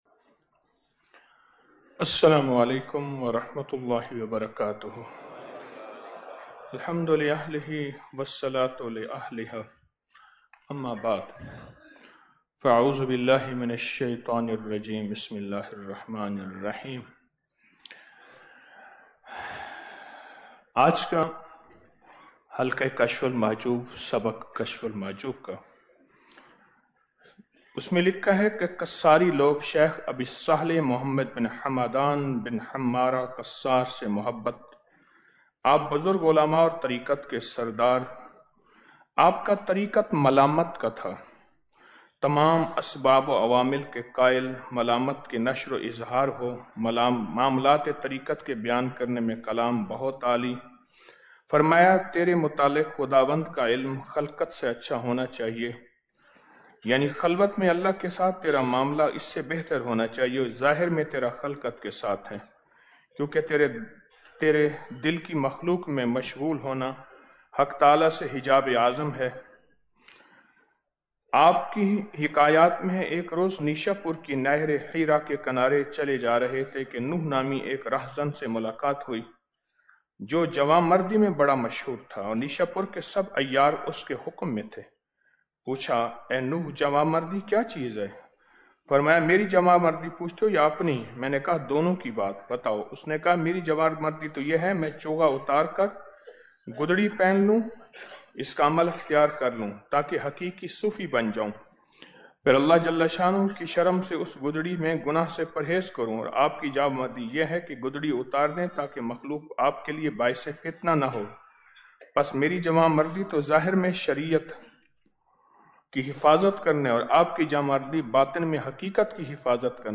آڈیو درس